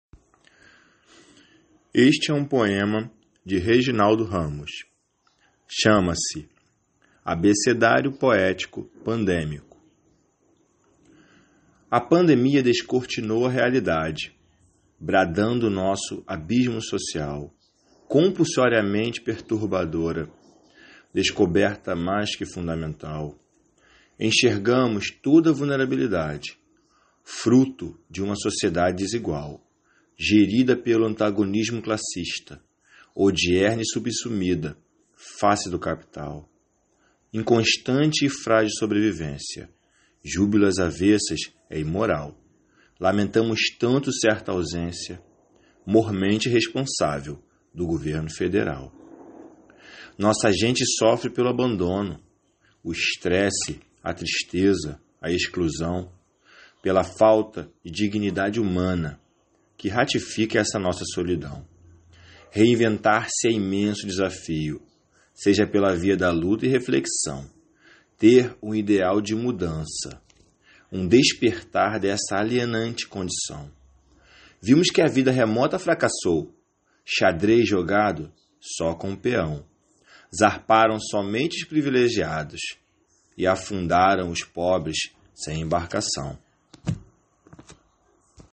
Técnica: poesia
Poesia com voz humana